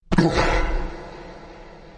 描述：无人机的声音是激烈和可怕的。激烈的咆哮肯定会让人高兴。